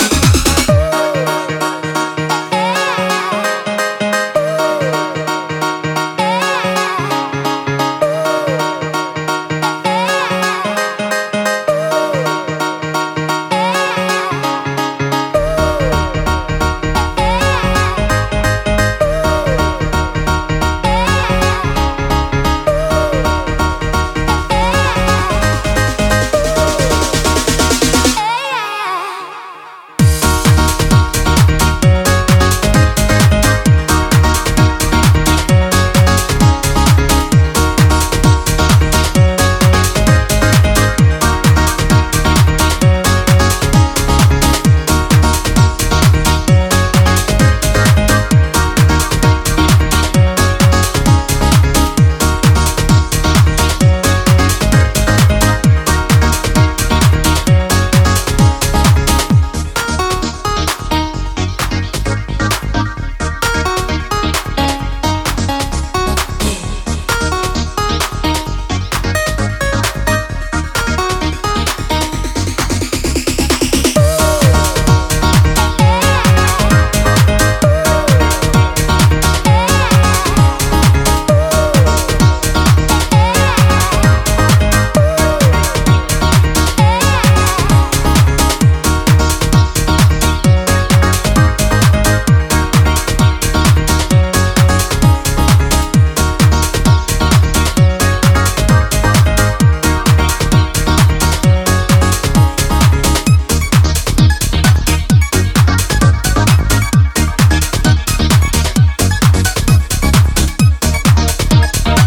two unashamedly old-school Scottish club cuts